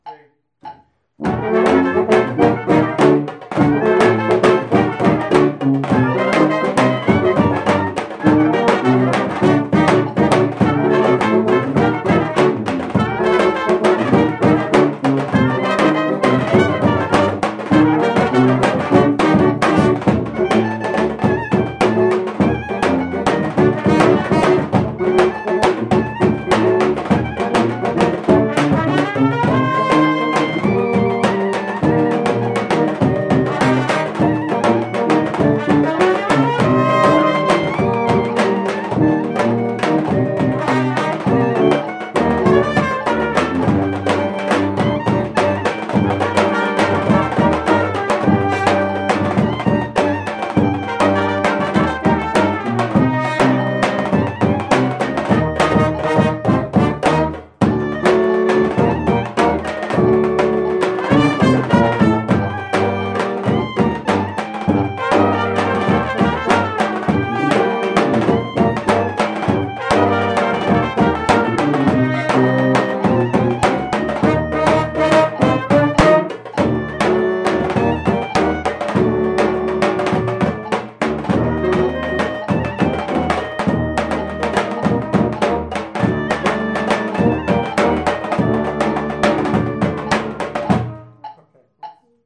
Rehearsal Recordings